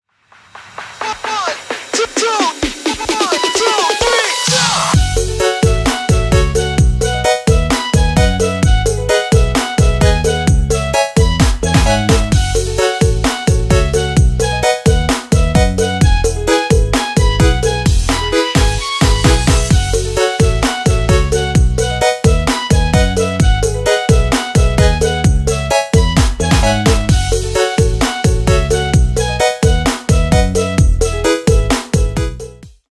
• Качество: 320 kbps, Stereo
весёлые